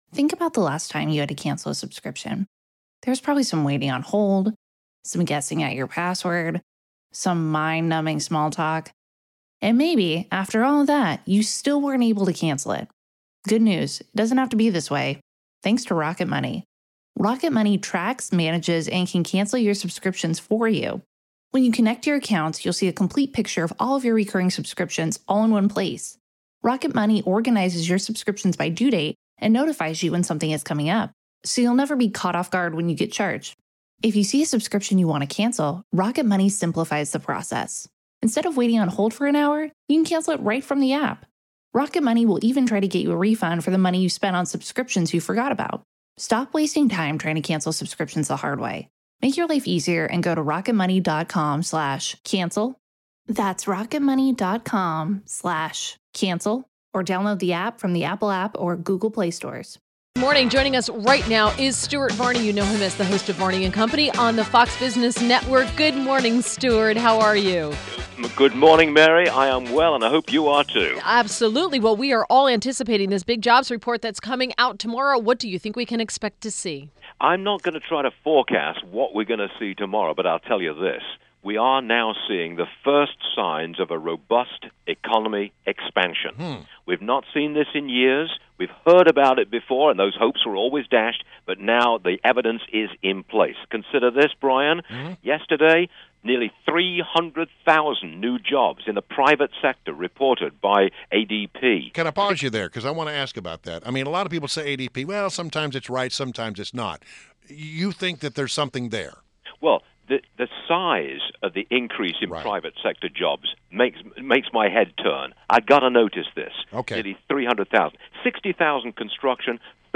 WMAL Interview - STUART VARNEY - 03.09.17
INTERVIEW – STUART VARNEY – HOST OF ‘VARNEY AND COMPANY’ ON FOX BUSINESS NETWORK